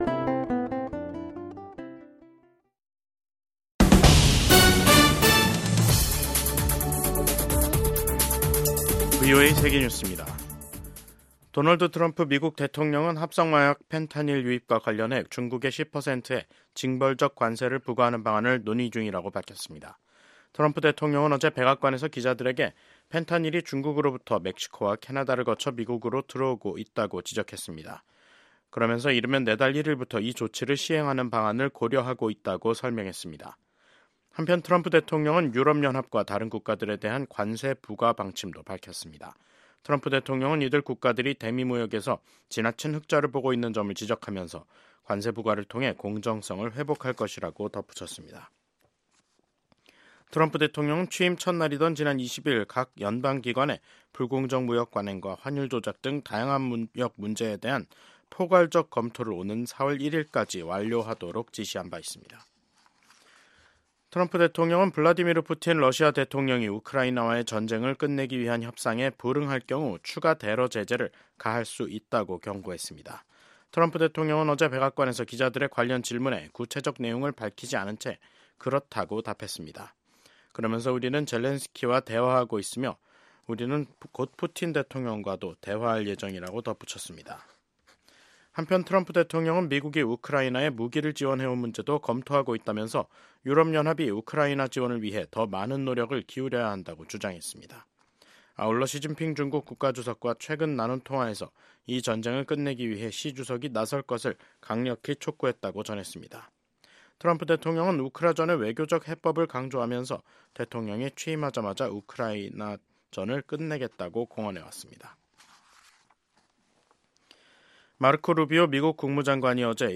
VOA 한국어 간판 뉴스 프로그램 '뉴스 투데이', 2025년 1월 22일 2부 방송입니다. 미국의 외교·안보 전문가들은 도널드 트럼프 대통령의 ‘북한 핵보유국’ 발언이 북한의 현실을 언급할 것일뿐 핵보유국 지위를 인정하는 것은 아니라고 분석했습니다. 트럼프 대통령의 취임 직후 대북 메시지가 협상 재개를 염두에 둔 유화적 신호에 그치지 않고 김정은 국무위원장을 압박하는 이중적 메시지라는 관측이 나옵니다.